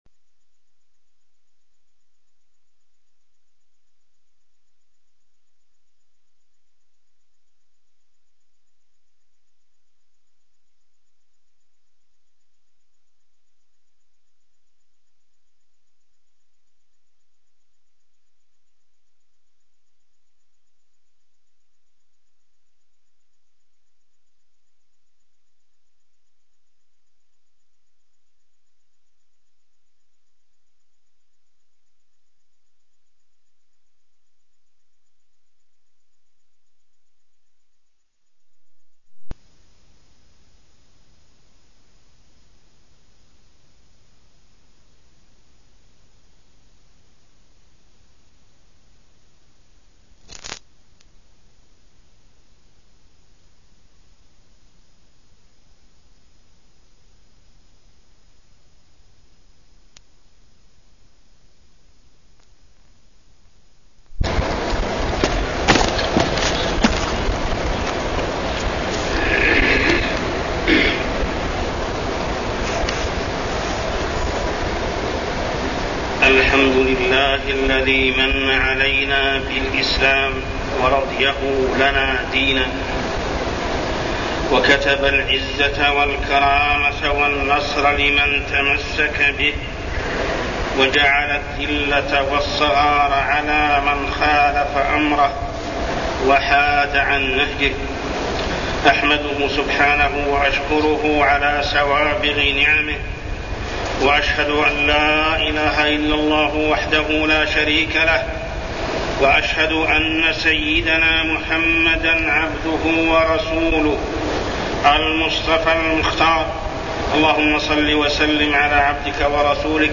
تاريخ النشر ١ ربيع الثاني ١٤١١ هـ المكان: المسجد الحرام الشيخ: محمد بن عبد الله السبيل محمد بن عبد الله السبيل تطبيق الإسلام